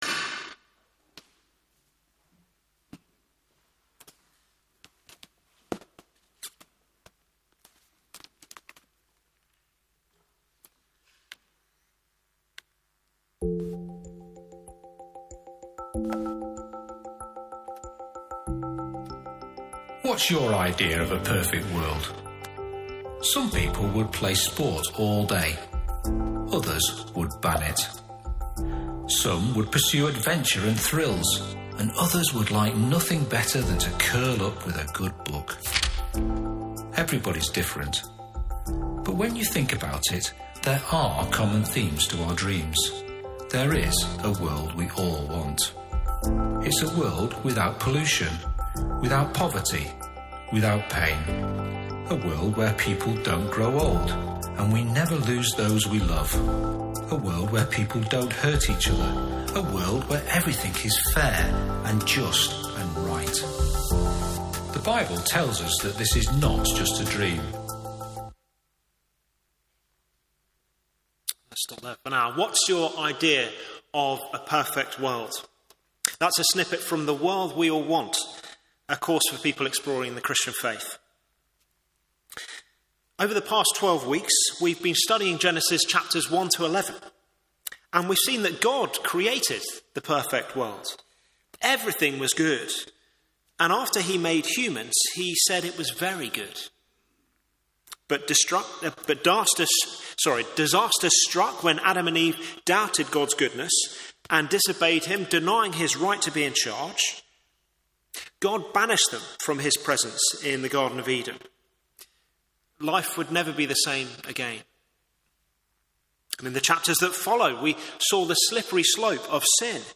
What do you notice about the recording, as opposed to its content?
Media for Morning Service on Sun 27th Jul 2025 10:30 Speaker